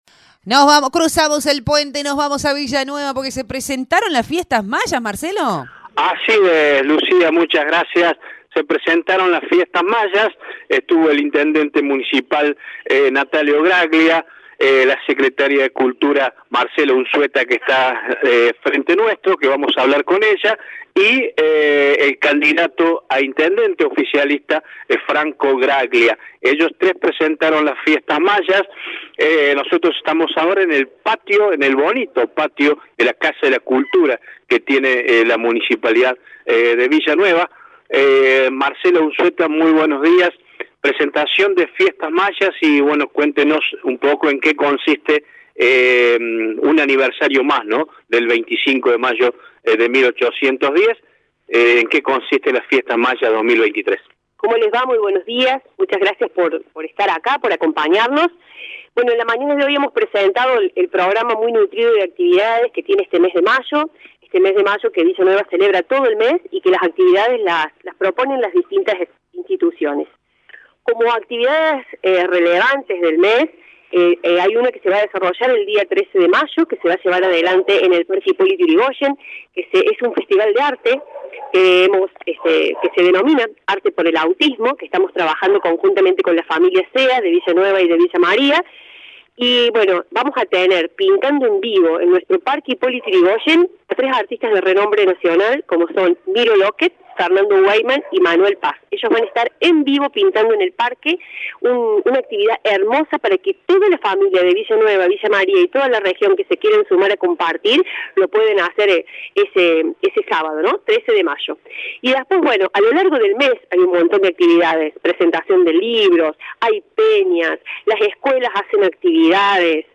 La secretaria de Cultura, Marcela Unzueta, brindó detalles al respecto.